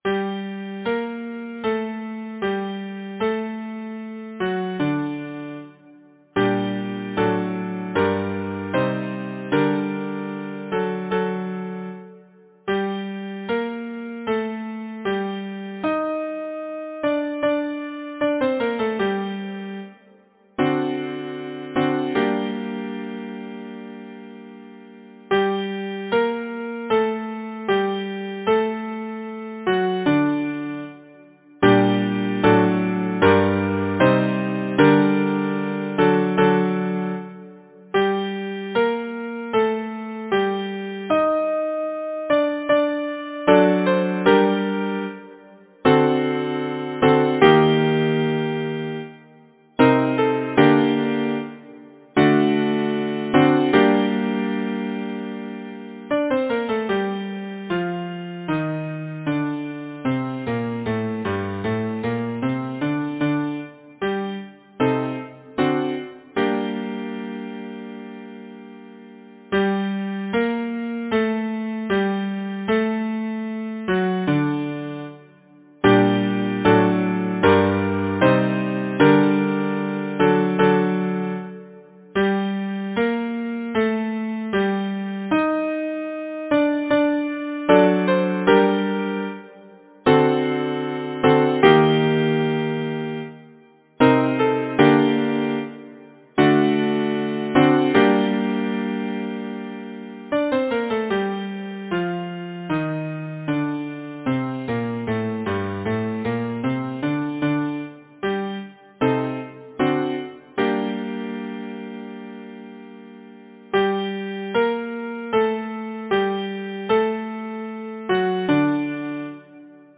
Title: The Cat’s predicament Composer: George Noyes Rockwell Lyricist: Number of voices: 4vv Voicing: SATB Genre: Secular, Partsong
Language: English Instruments: Piano